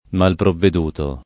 malprovveduto
vai all'elenco alfabetico delle voci ingrandisci il carattere 100% rimpicciolisci il carattere stampa invia tramite posta elettronica codividi su Facebook malprovveduto [ malprovved 2 to ] o mal provveduto [id.] agg.